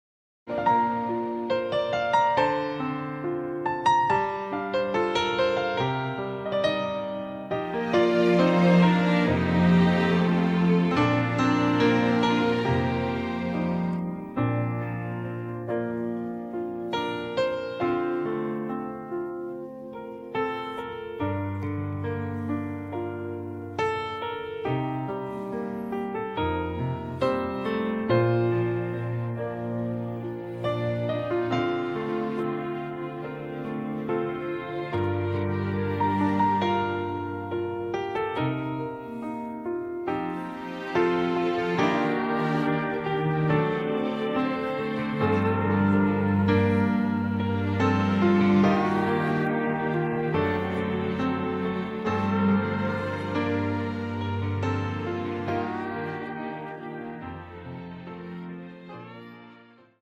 음정 원키 4:42
장르 가요 구분 Voice Cut